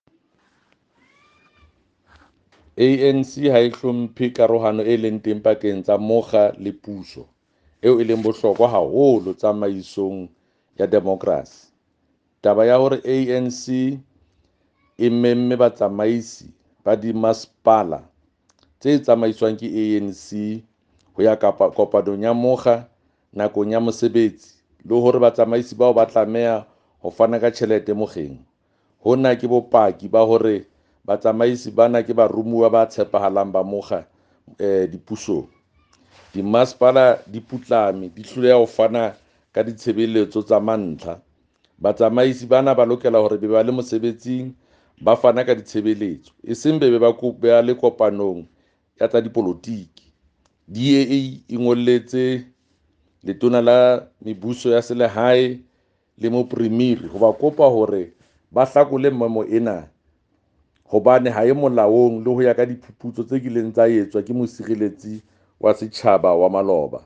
Sesotho Jafta Mokoena MPL